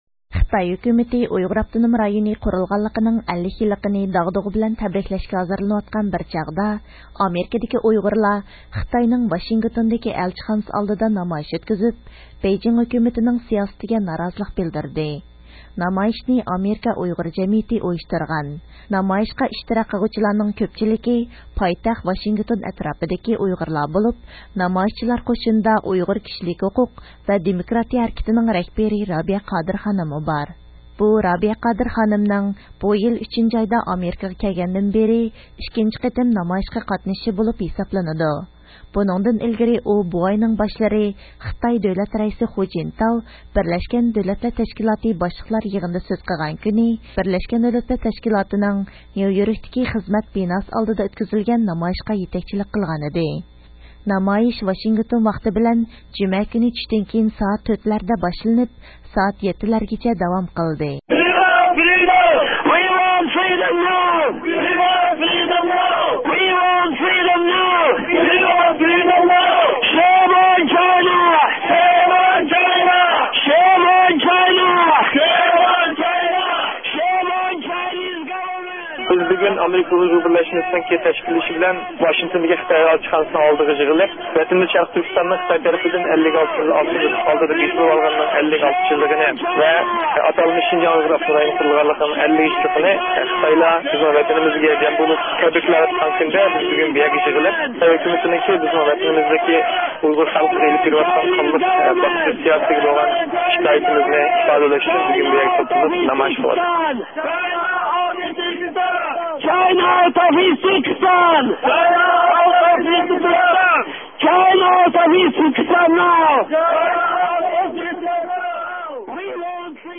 نامايىشچىلار خىتاي دائىرىلىرىنى ئۇيغۇر ئاپتونوم رايونىدىن چېكىنىپ چىقىش، ئۆلۈم جازاسىنى توختىتىش ۋە سىياسى مەھبۇسلارنى قويۇپ بېرىشكە چاقىرىدىغان شۇئارلار توۋلىدى.